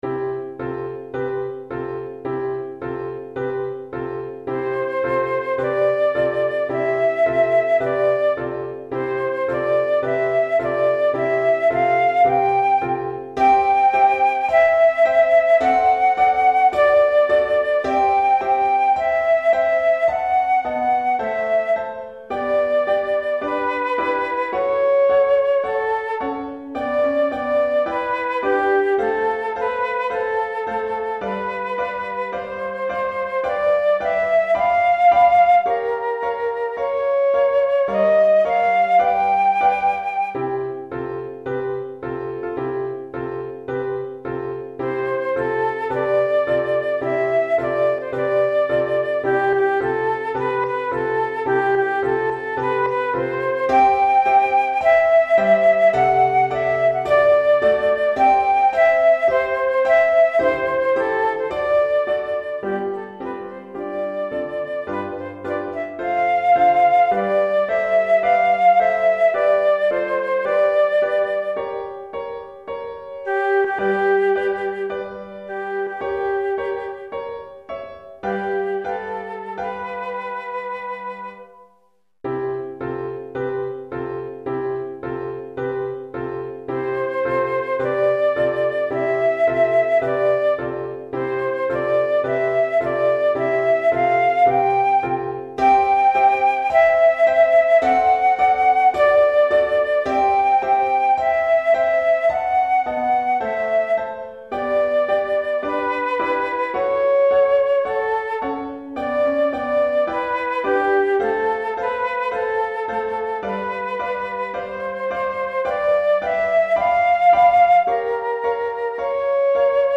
Pour flûte et piano DEGRE CYCLE 1